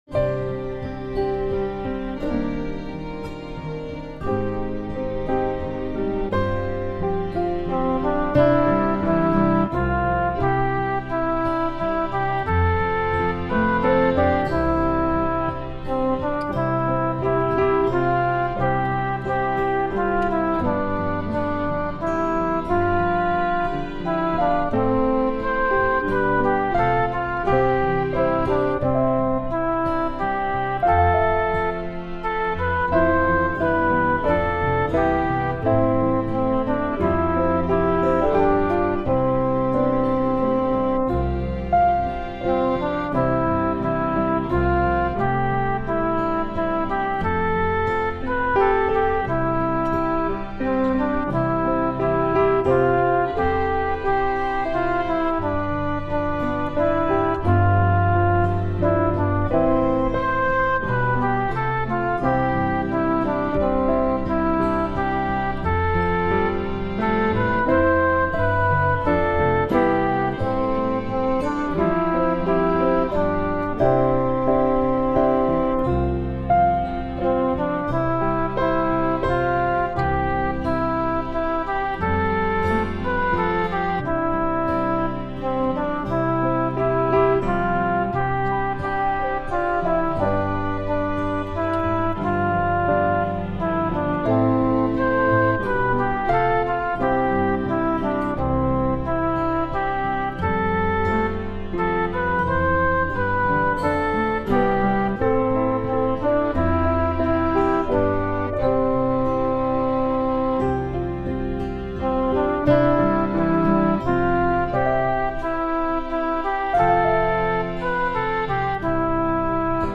a vaguely Irish air